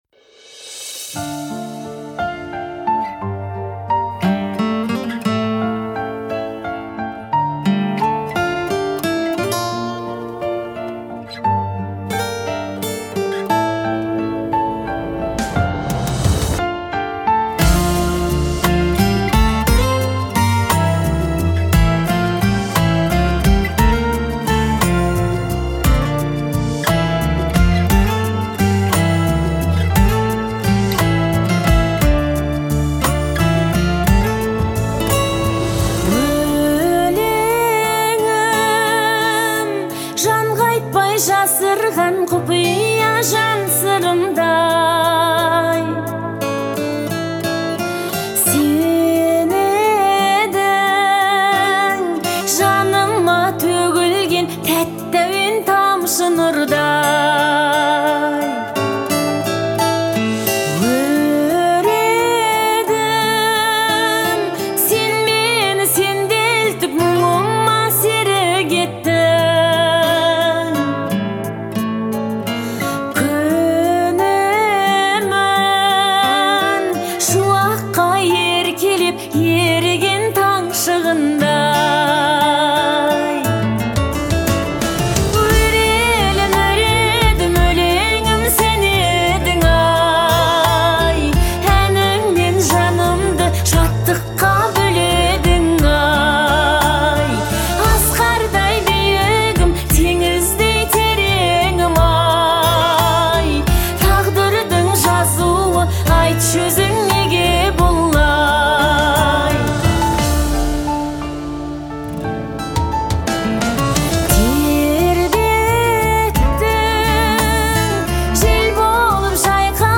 а традиционные инструменты создают атмосферу тепла и уюта.